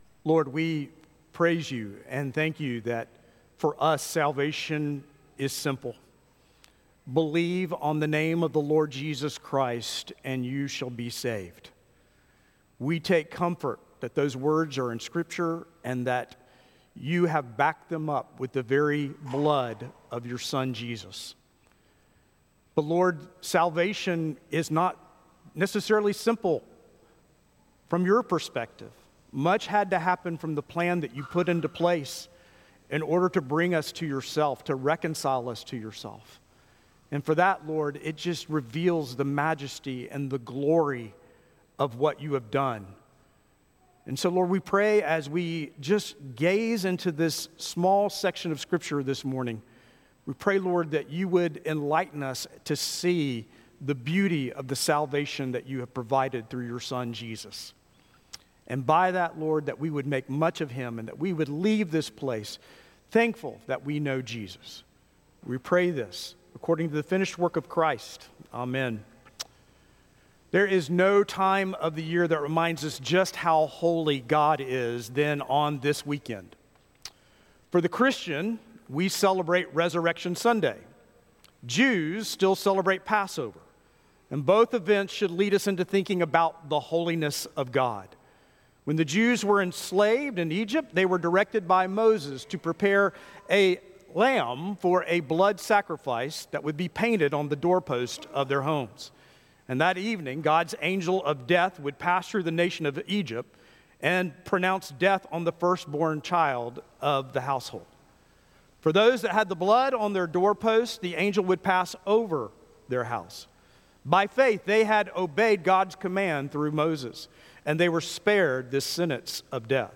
Weekly Sermons from Providence Baptist Church in Huntsville Alabama